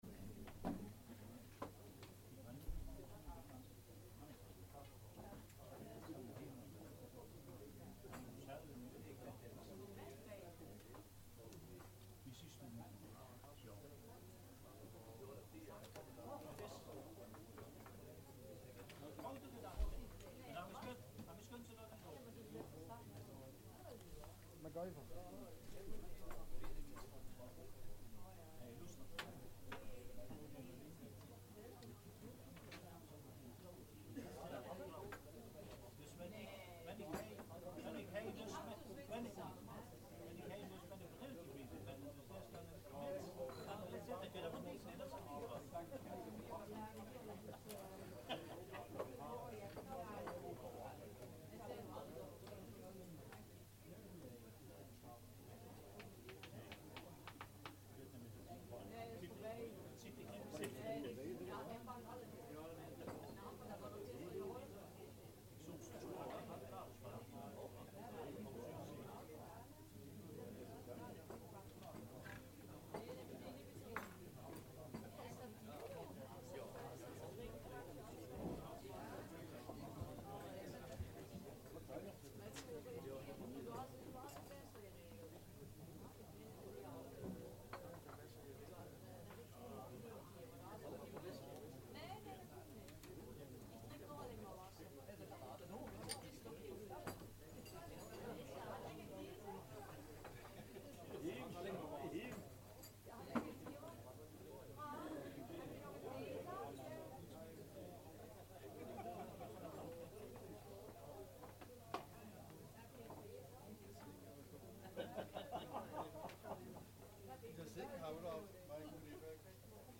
Agenda Kerkrade - Gemeenteraad woensdag 20 december 2023 19:00 - 22:00 - iBabs Publieksportaal
I.v.m. de verbouwing van de raadzaal is de raadsvergadering in het Martin Buber, Marktstraat 6, Kerkrade.
Publiek kan de vergadering wel bijwonen.